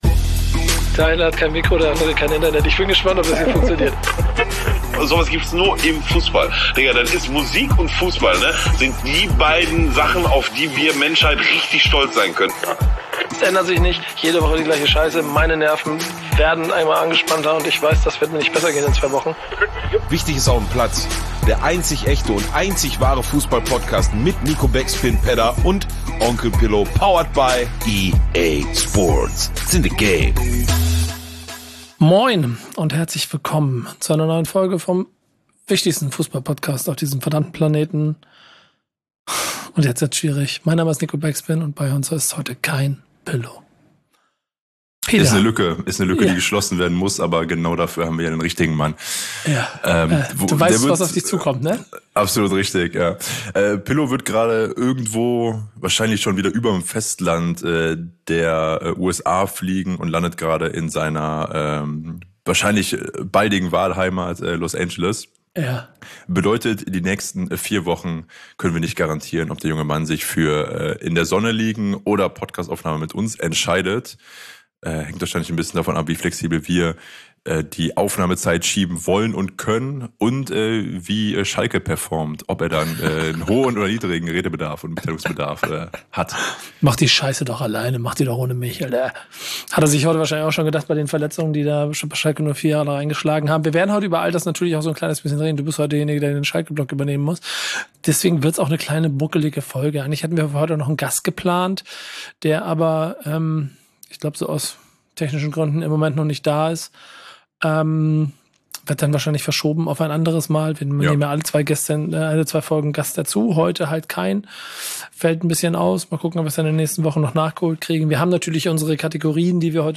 diese Lüge füllen wir mit der Extraportion Stimmimitationen und einer Sprachmemo aus Los Angeles.